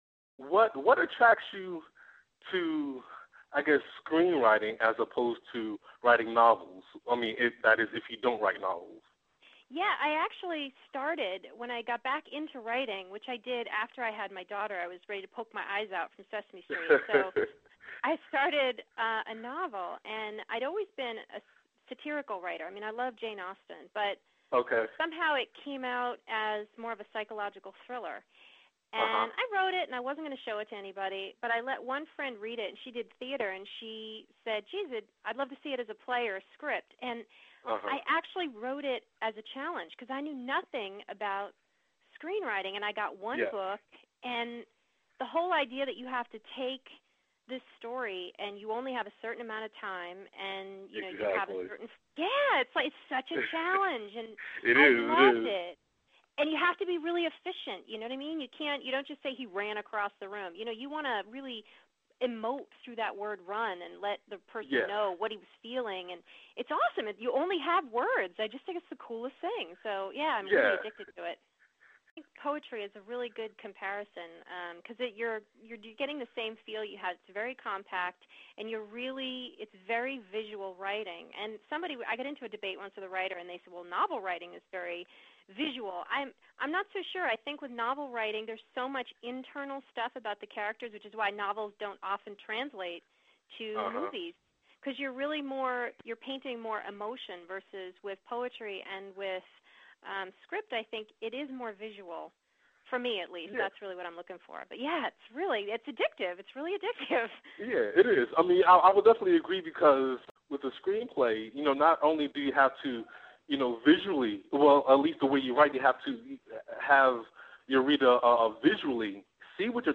We had a lot of fun and laughed a lot as you’ll hear.